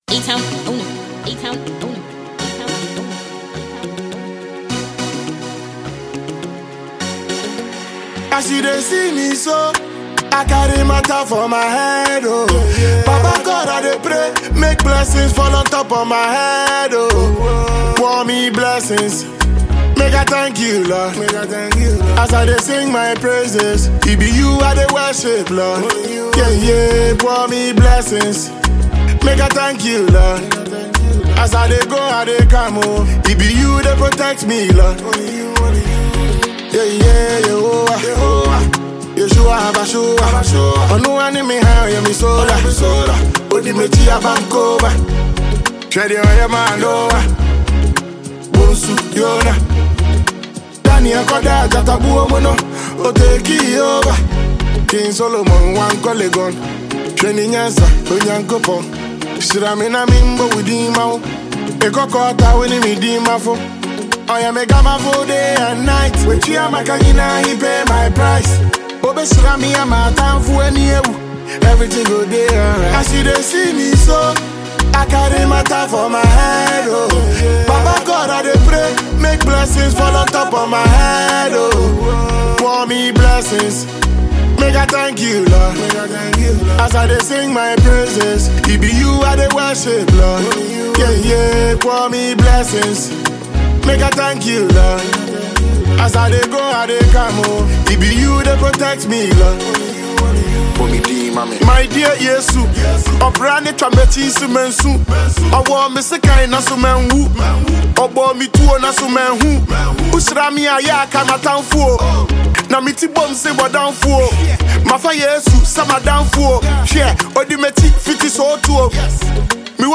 Heavyweight Ghanaian rapper